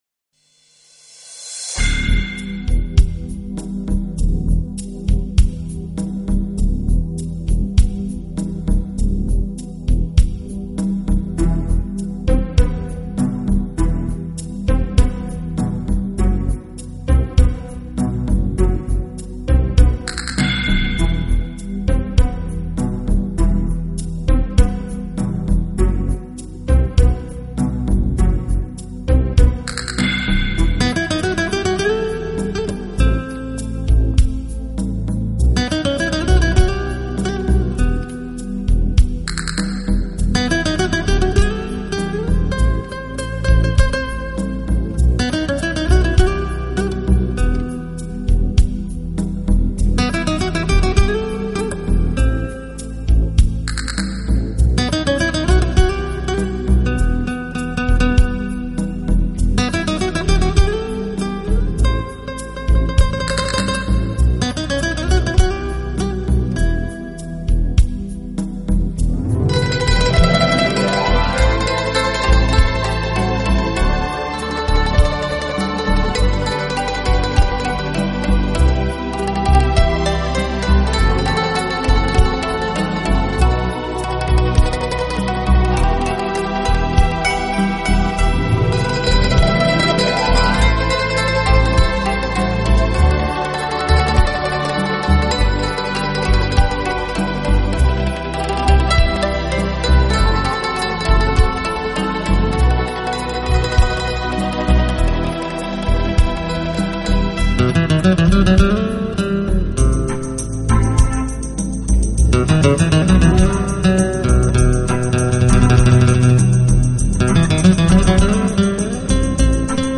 音乐流派：Instrumental / New Age
他和他的管弦乐队组成的乐队以吉他为主，成功地将吉他和管弦乐相融合在一起，以